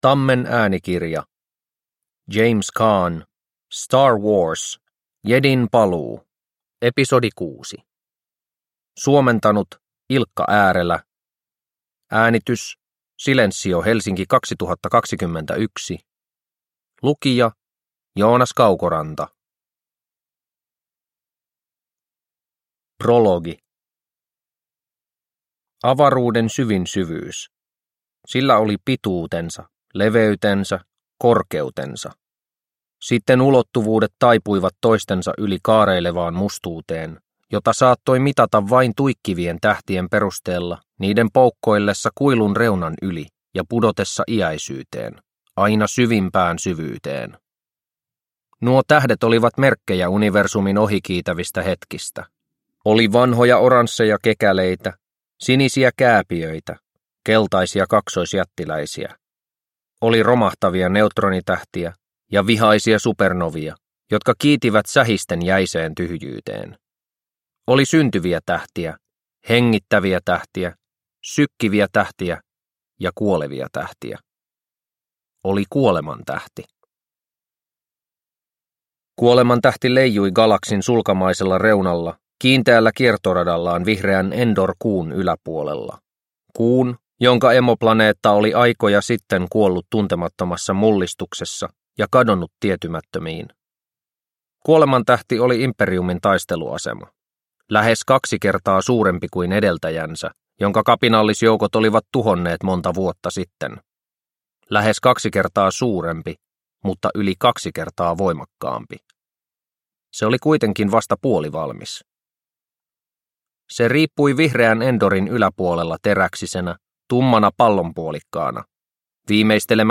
Star Wars. Jedin paluu – Ljudbok – Laddas ner